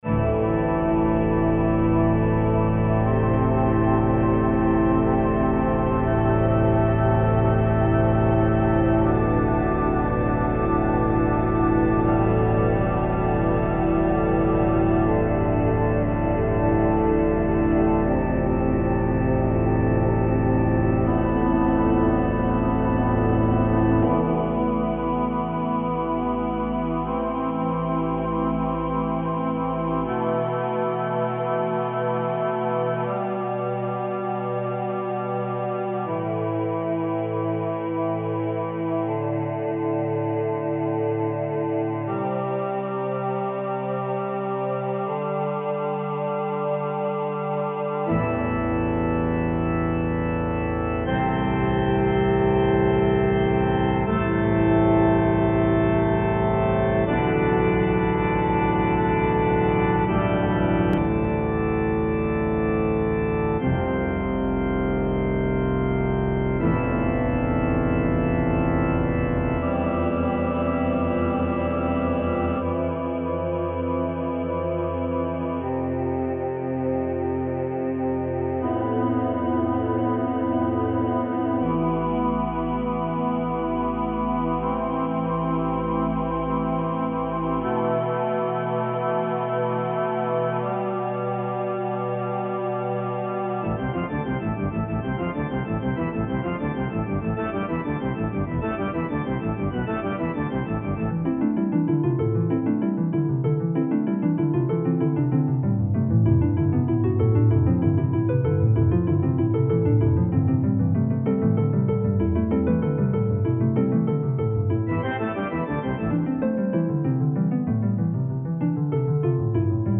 Heavy metal
Experimental
Prog rock